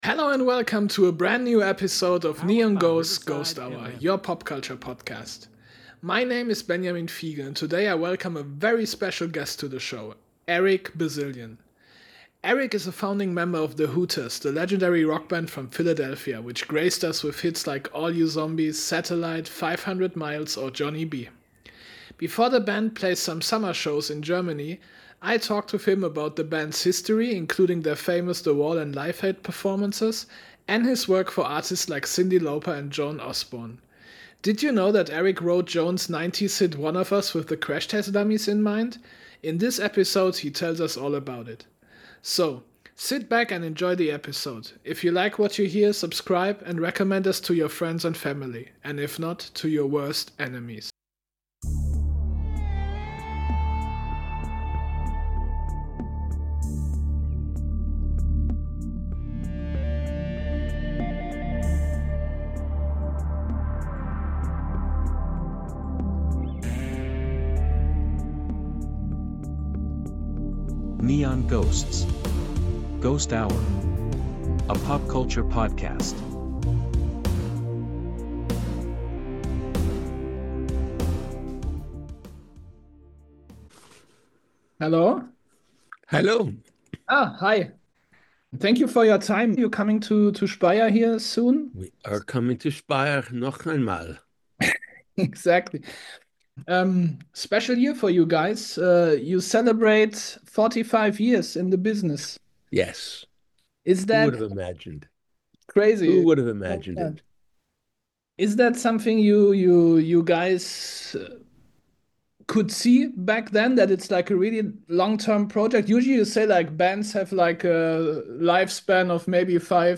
Im Interview: Eric Bazilian (The Hooters) - The secret behind one of the 90s biggest hits ~ NEON GHOSTS: GHOST HOUR Podcast